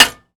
R - Foley 90.wav